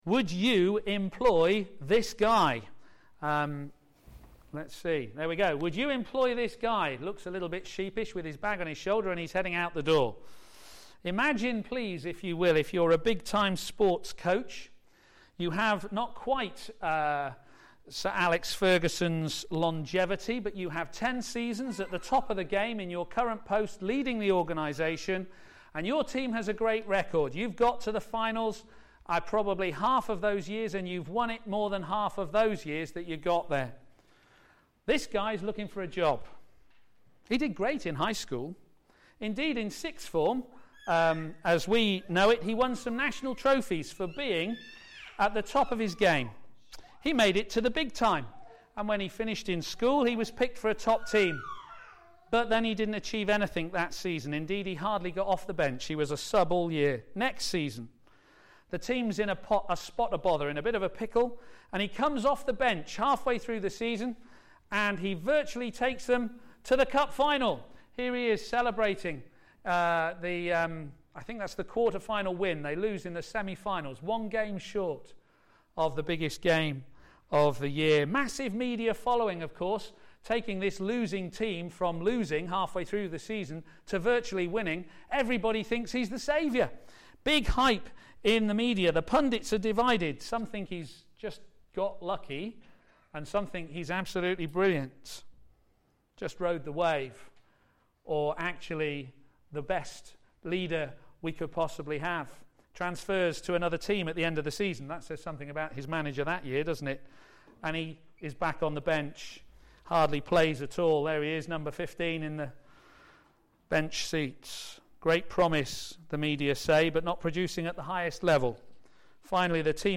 Media for a.m. Service on Sun 16th Jun 2013 10:30
Theme: A Man Who Knows His Children Sermon